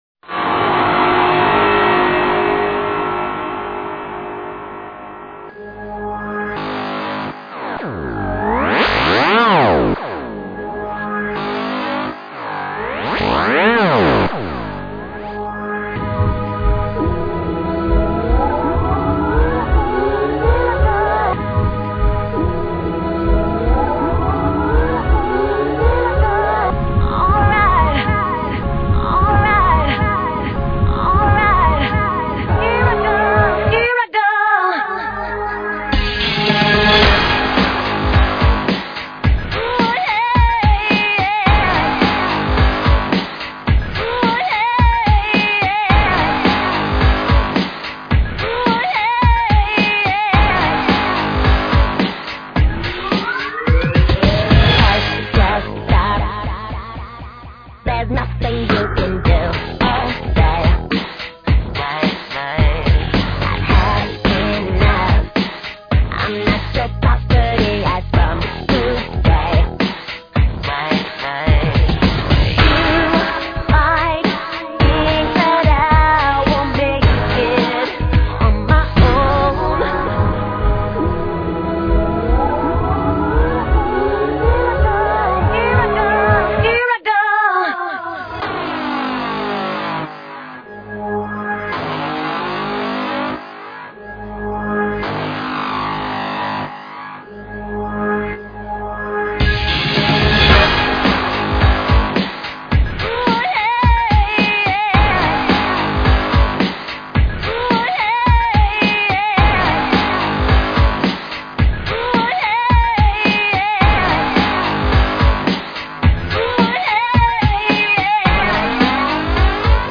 .MP3    (MPEG 2.5 layer 3, 24KB per second, 11,025 Hz, Mono)
Pop